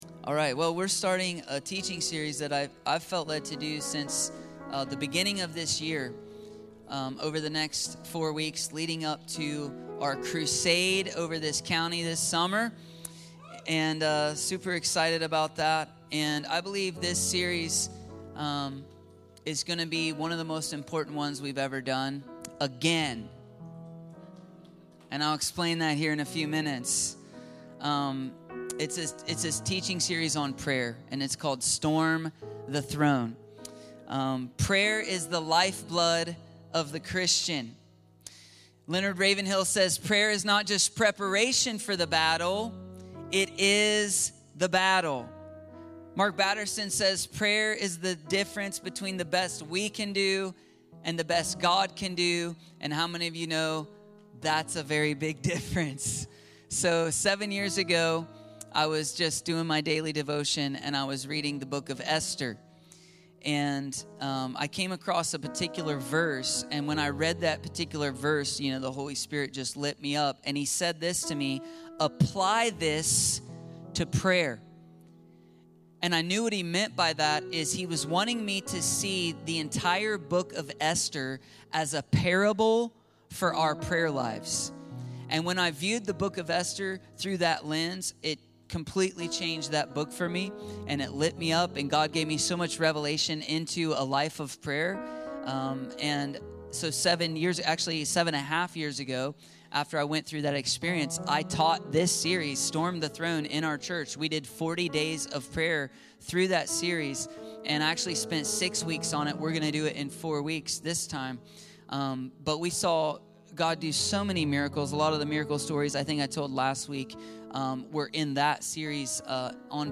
STORM THE THRONE - Storm The Throne ~ Free People Church: AUDIO Sermons Podcast